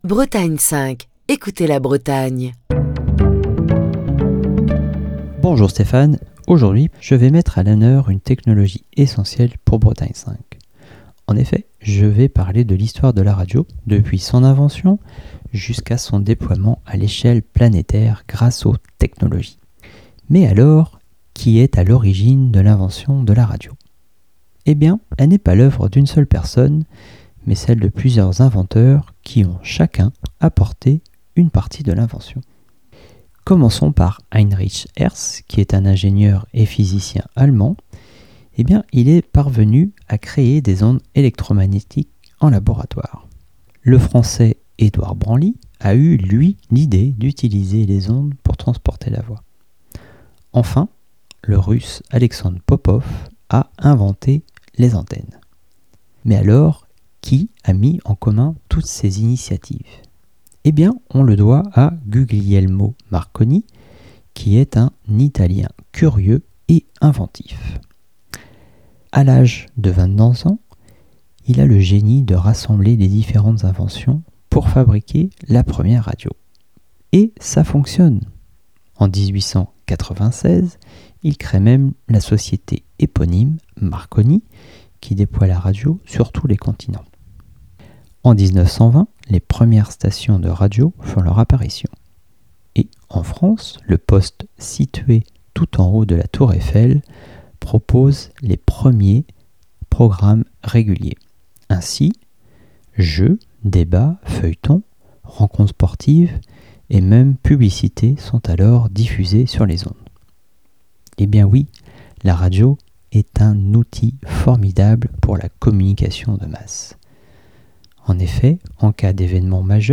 Chronique du 10 avril 2024. De son invention jusqu'au déploiement du DAB+, la radio n'a cessé d'innover tant au niveau des contenus, qu'au niveau technique. Aujourd'hui, la radio entame sa mue numérique et se prépare à un avenir prometteur.